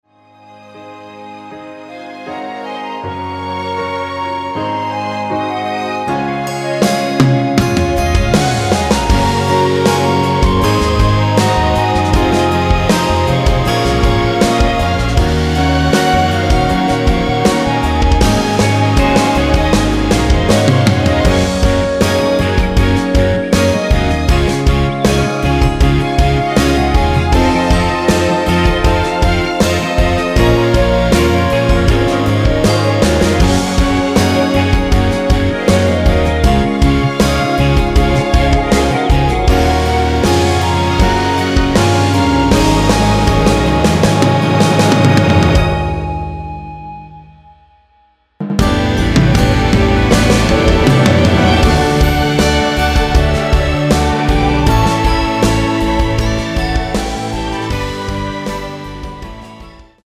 Db
◈ 곡명 옆 (-1)은 반음 내림, (+1)은 반음 올림 입니다.
멜로디 MR이란
노래방에서 노래를 부르실때 노래 부분에 가이드 멜로디가 따라 나와서
앞부분30초, 뒷부분30초씩 편집해서 올려 드리고 있습니다.
중간에 음이 끈어지고 다시 나오는 이유는